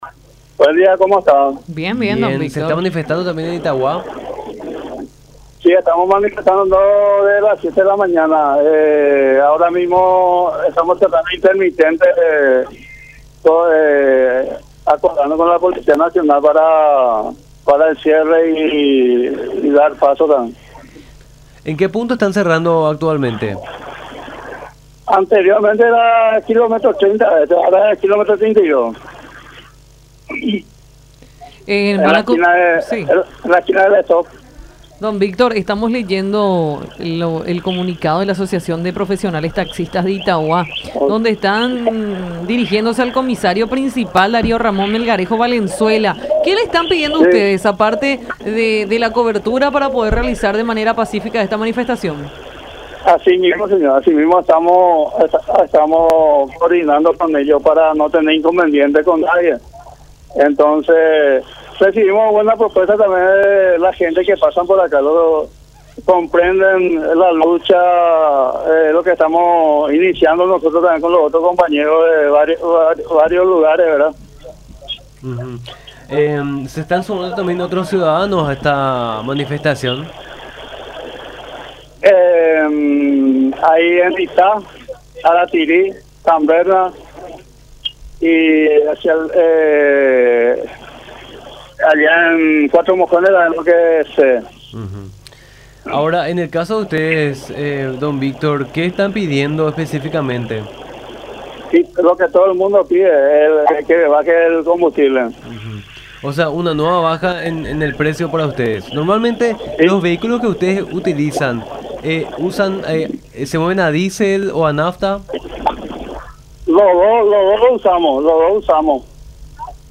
en contacto con Nuestra Mañana por La Unión.